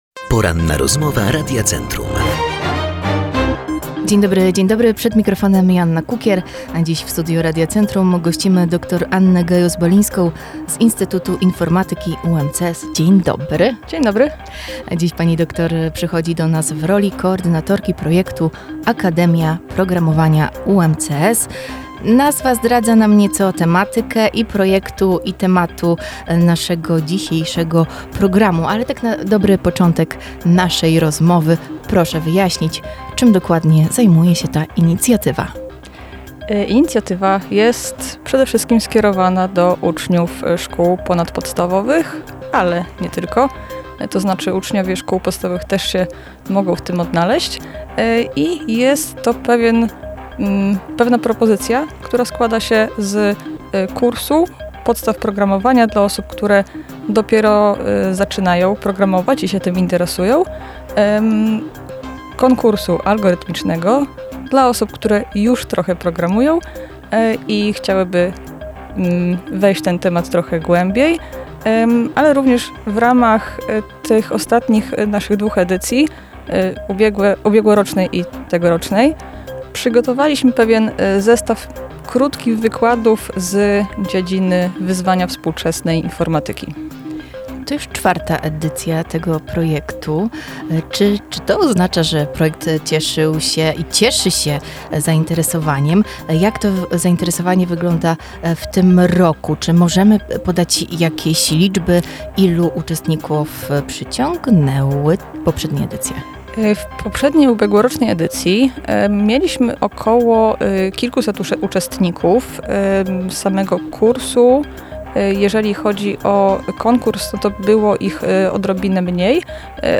Za nami Poranna Rozmowa Radia Centrum.
ROZMOWA